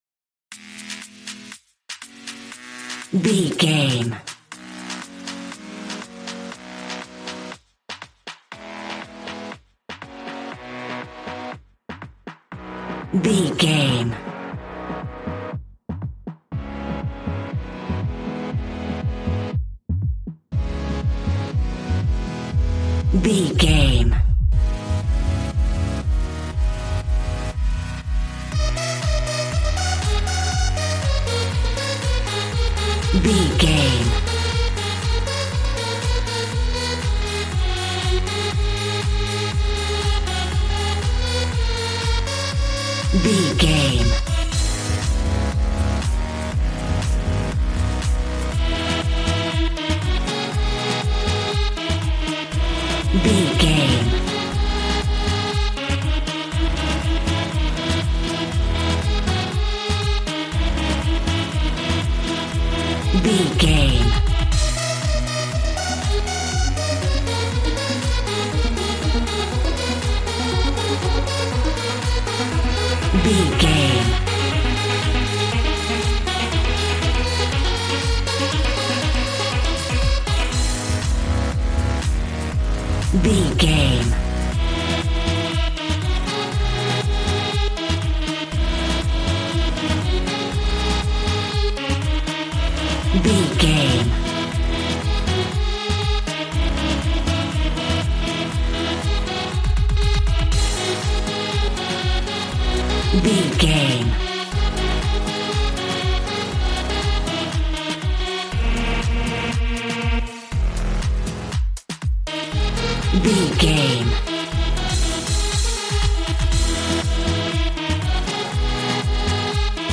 Aeolian/Minor
A♭
Fast
uplifting
lively
groovy
synthesiser
drums